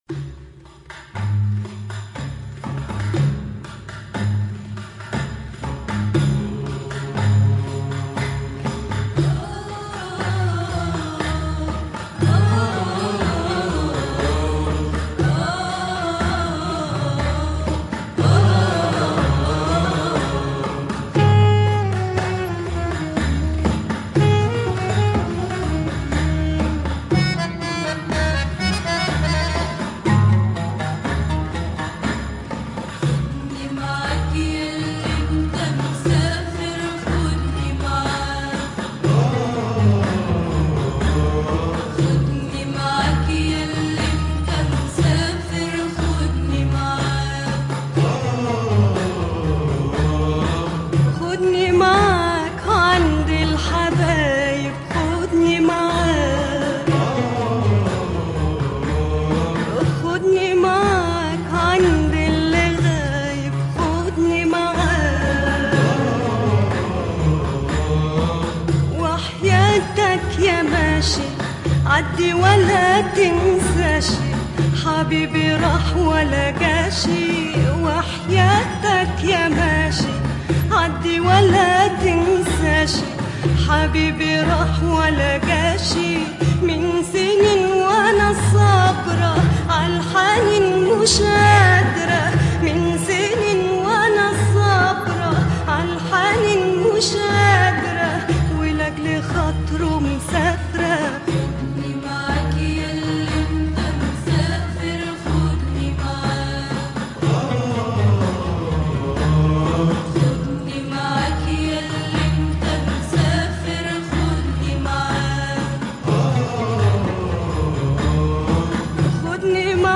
Some marks on the record.